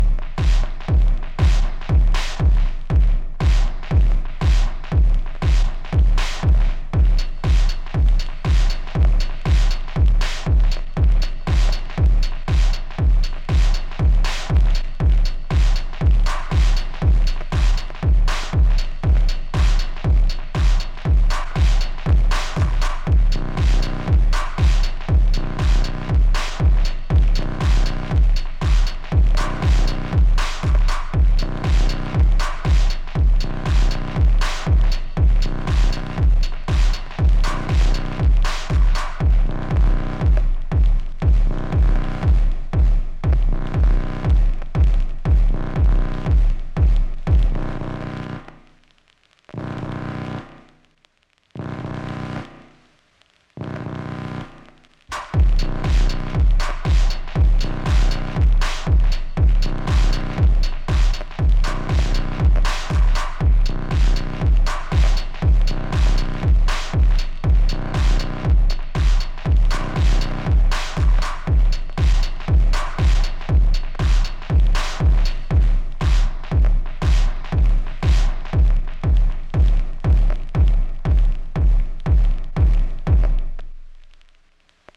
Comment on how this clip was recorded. This is to test different drive settings that sound very different with different speakers and headphones. I’d say, still too much reverb, and when I listen to it compressed and from computer, mid seem to prominent. However, bass doesn’t mud up everything, this is a plus already.